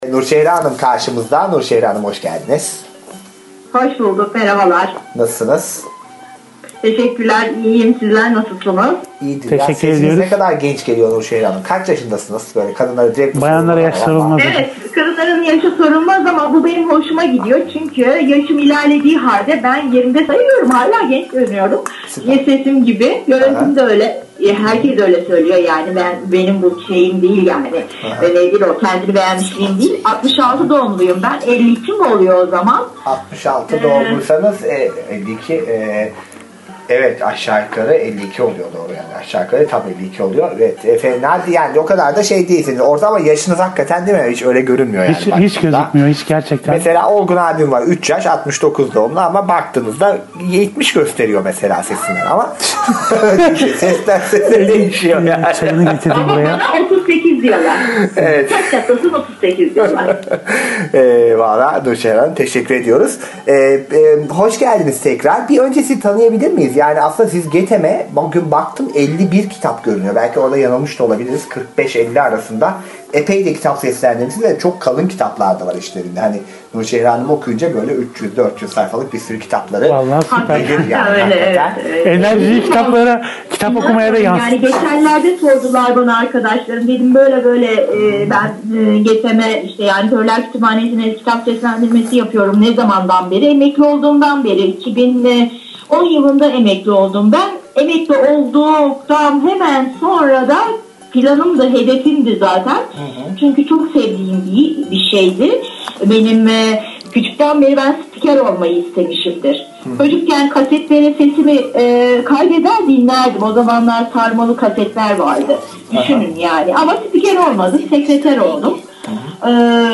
Gönüllü okuyucu röportajları